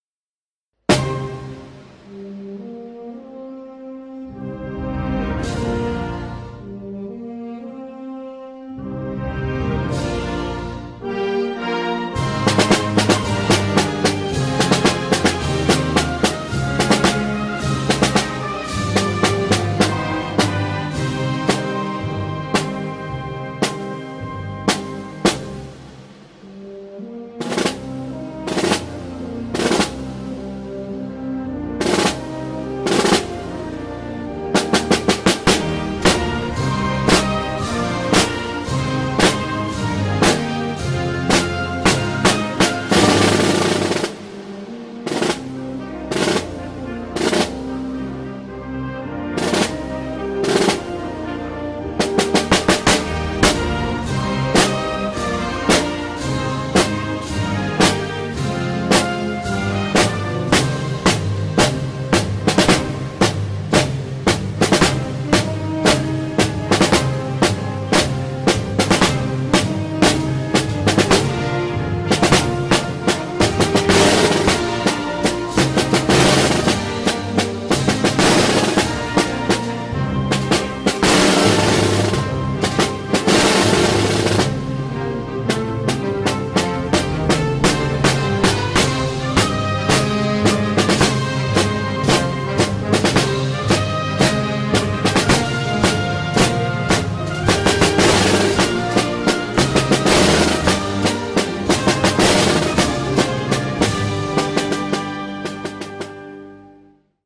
Percusión
CAJA
jerusalen_caja_solo.mp3